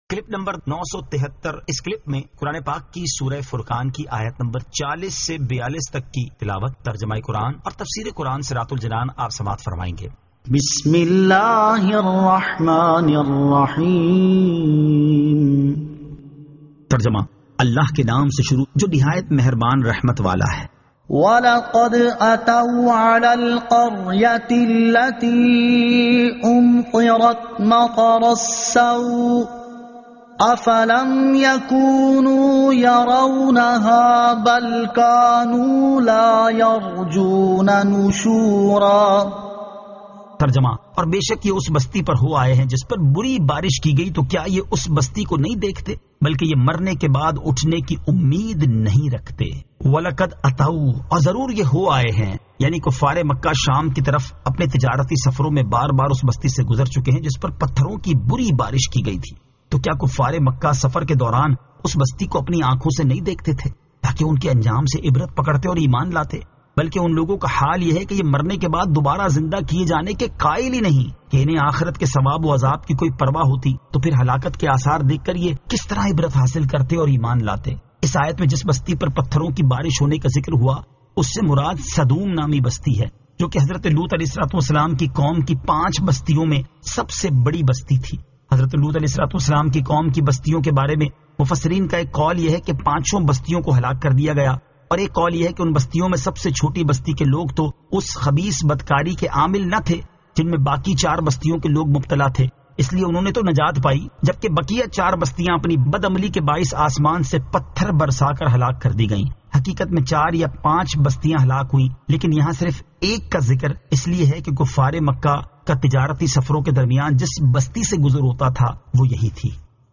Surah Al-Furqan 40 To 42 Tilawat , Tarjama , Tafseer
2022 MP3 MP4 MP4 Share سُوْرَۃُ الْفُرقَانِ آیت 40 تا 42 تلاوت ، ترجمہ ، تفسیر ۔